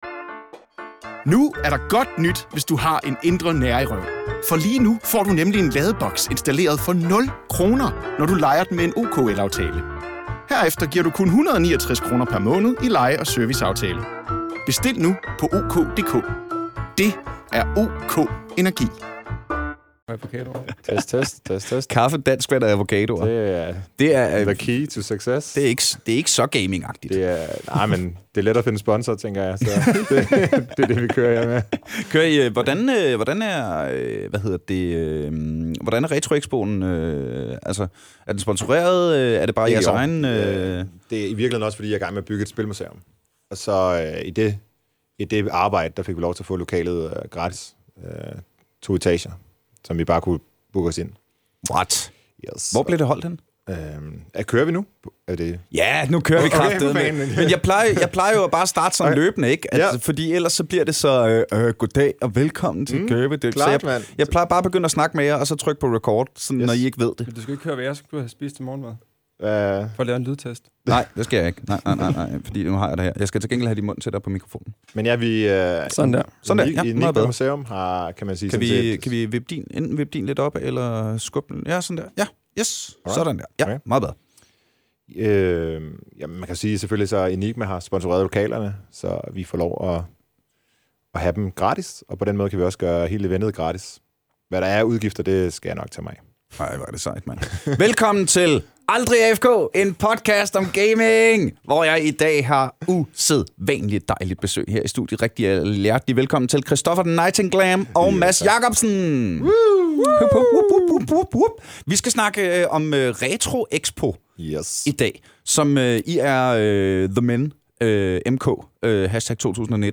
Vi har fået fint besøg af 2 ud af 3 af Danmarks ukronede retrokonger! Vi snakker Bip Bip Bar, EM i Tetris og livet som Nintendo-Pusher!